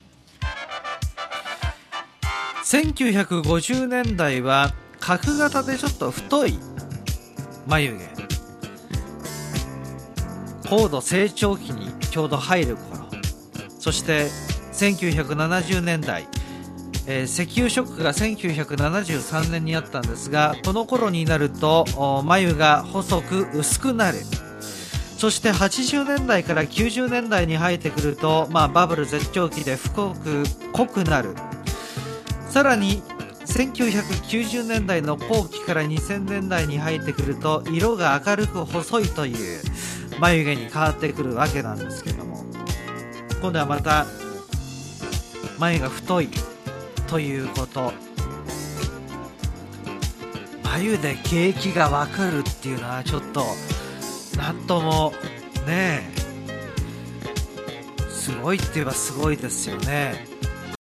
Japanese Lessons
Slow Speed